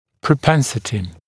[prə’pensətɪ][прэ’пэнсэти]склонность, предрасположение